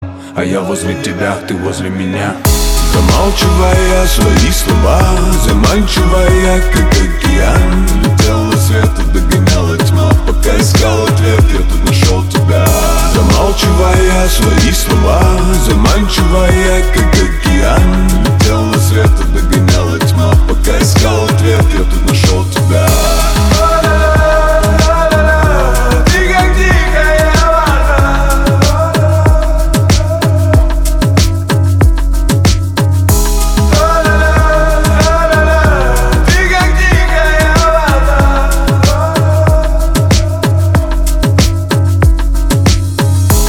• Качество: 320, Stereo
мужской вокал
красивый мужской голос
романтичные